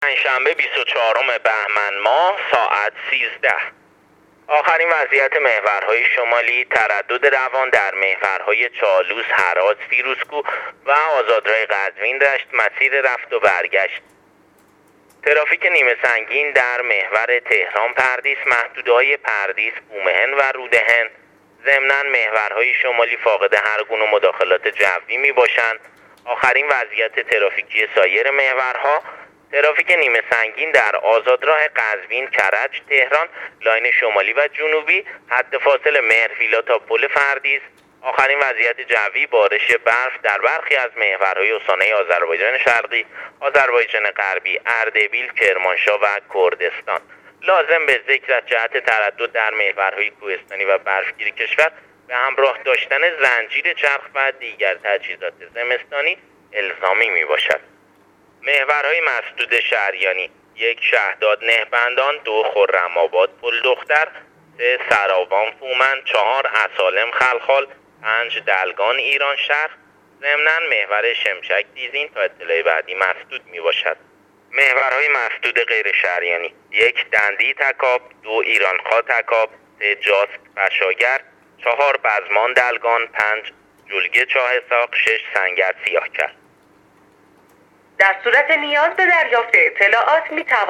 گزارش رادیو اینترنتی از آخرین وضعیت ترافیکی جاده‌ها تا ساعت ۱۳ پنجشنبه ۲۴ بهمن‌ماه ۱۳۹۸